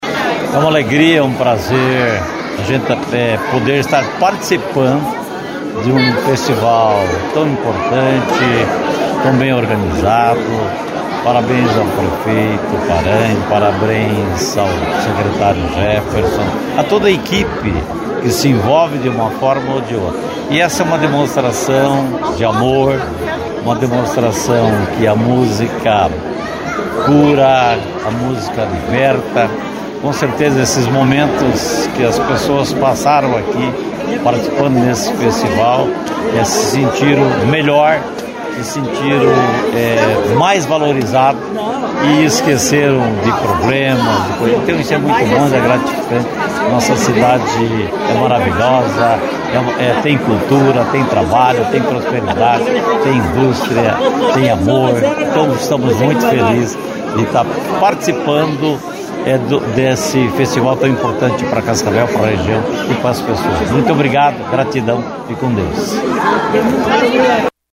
O vice-prefeito Renato SIlva disse que a cidade é abençoada por ter atividades como esta.